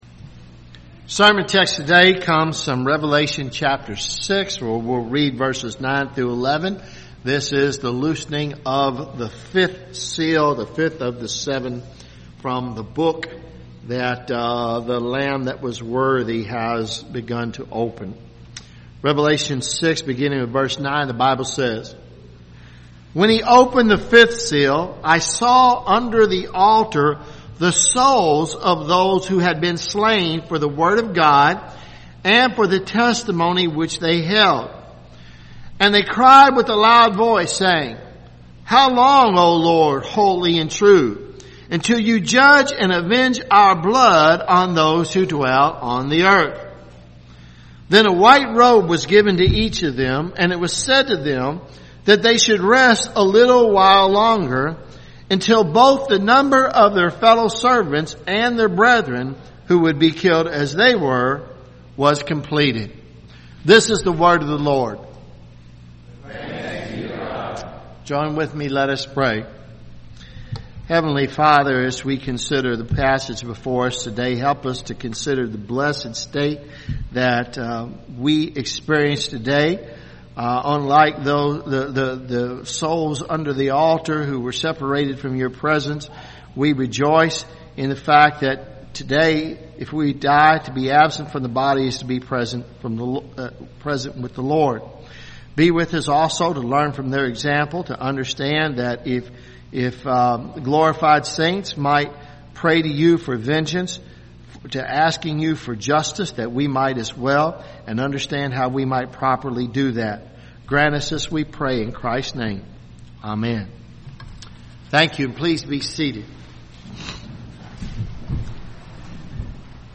Revelation sermon series , Sermons